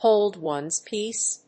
hóld one's péace